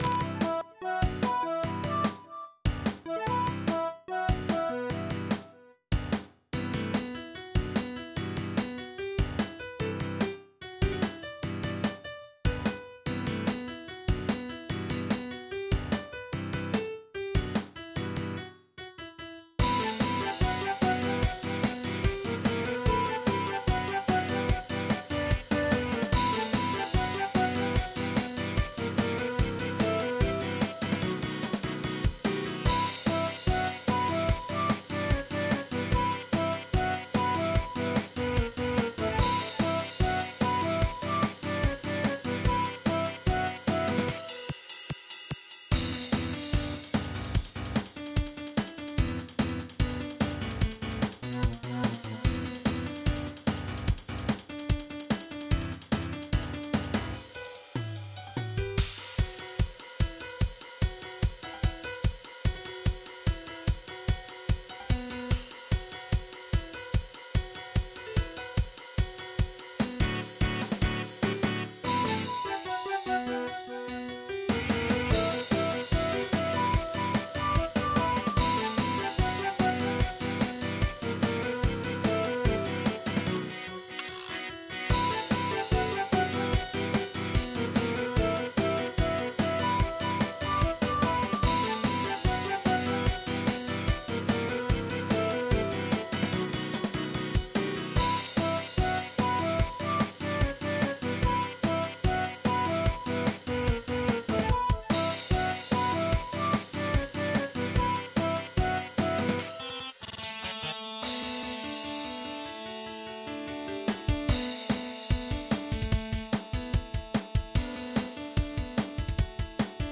MIDI 84.02 KB MP3 (Converted) 3.81 MB MIDI-XML Sheet Music